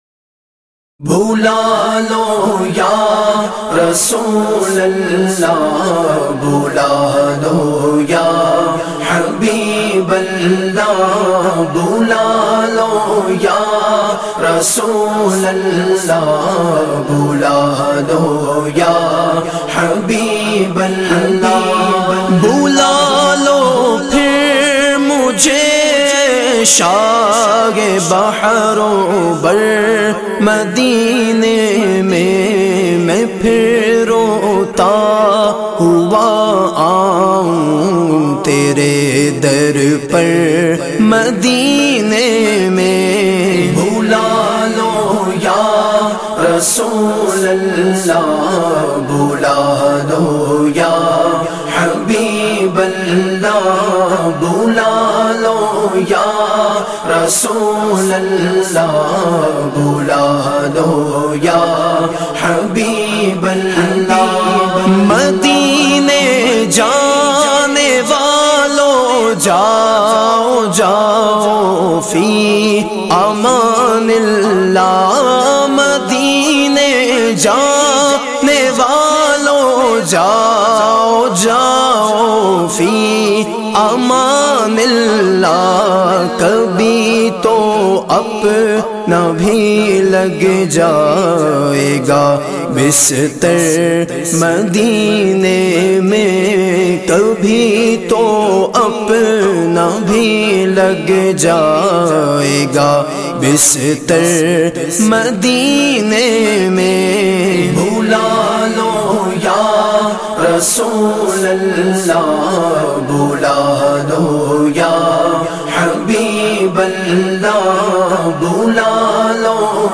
naat khuwan